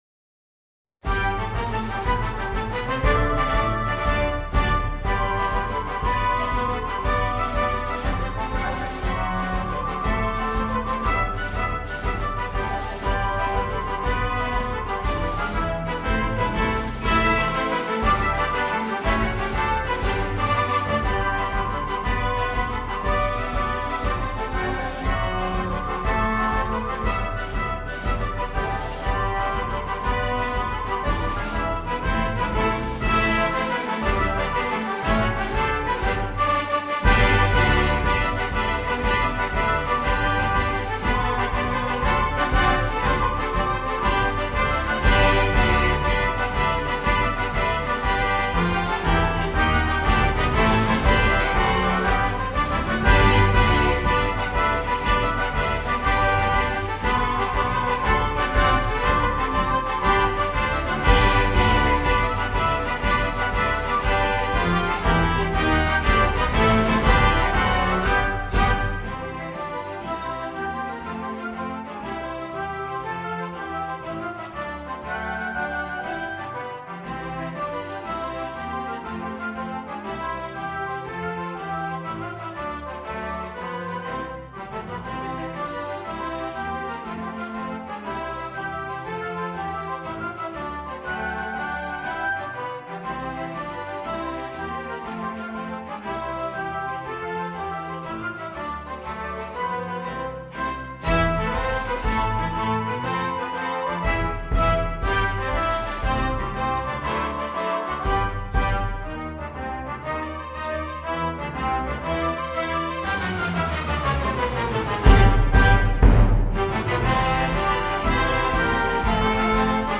Title Behind The Hounds Subtitle Dedication Composer Allen, Thomas S. Arranger Date 1900 Style March , Two-step Instrumentation Salon Orchestra Score/Parts Download Audio File:Behind the Hounds - full.mp3 Notes